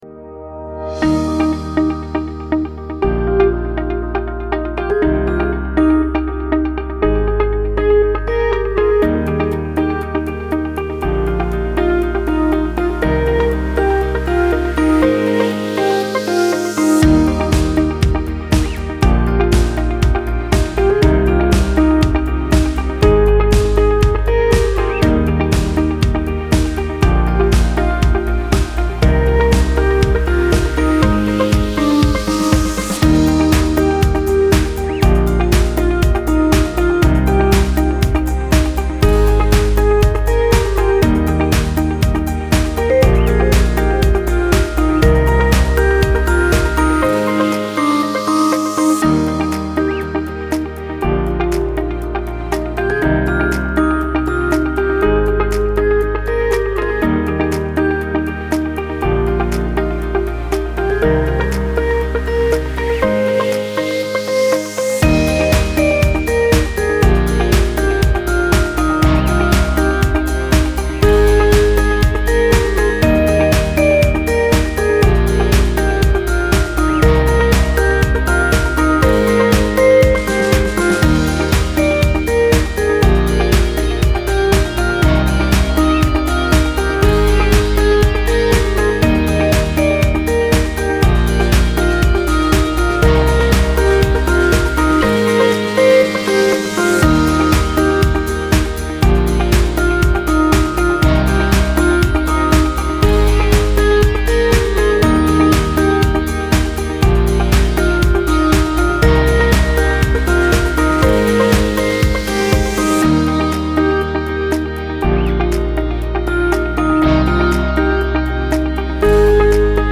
Genres: TiktokEnergetic